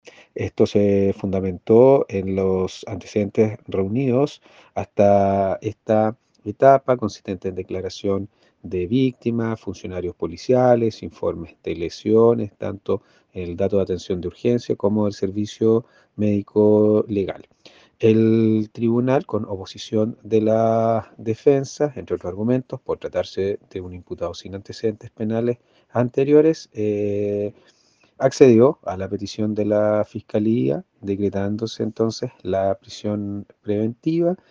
El persecutor expuso además que tras la petición, el tribunal de la capital provincial accedió a otorgar la cautelar de prisión preventiva.